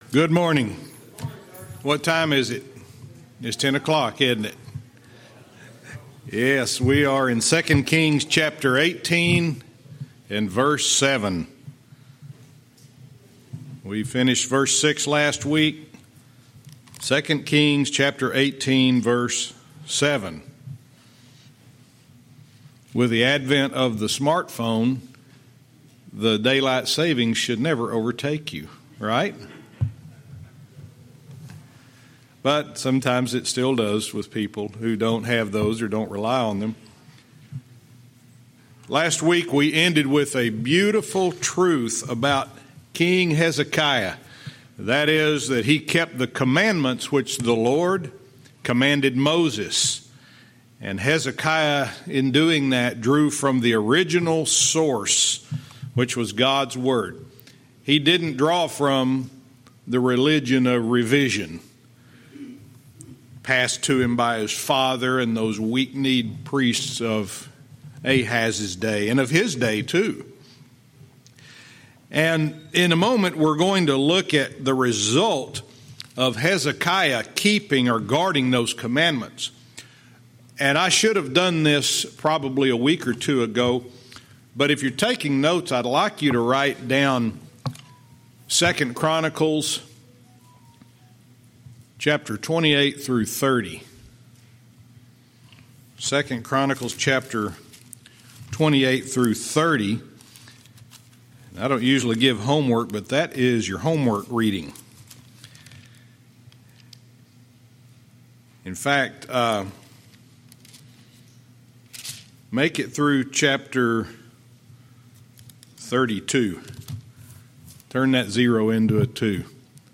Verse by verse teaching - 2 Kings 18:7,8